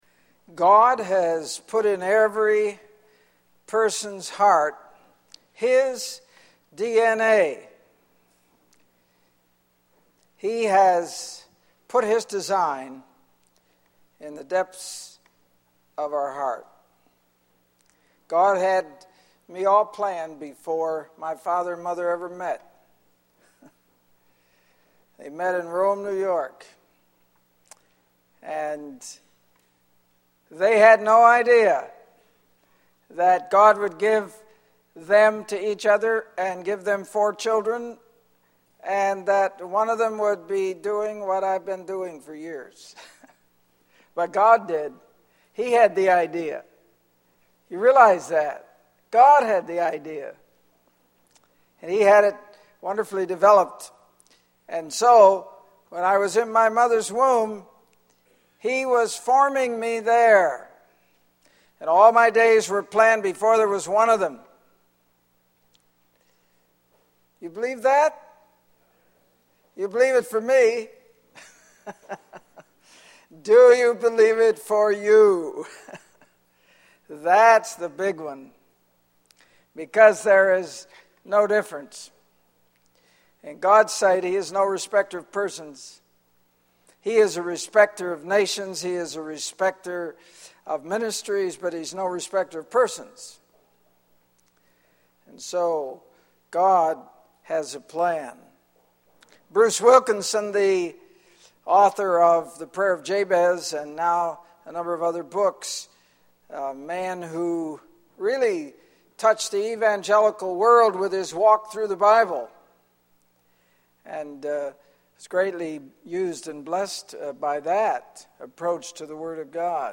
In this sermon, the speaker emphasizes the importance of discovering one's deepest heart's desire as a way to understand God's design for their life. The speaker provides five steps to help individuals get in touch with their heart's desires, including recognizing God's ownership and interest in their life, studying what God says about their purpose, yielding their body to God, and refusing to conform to the world's thinking.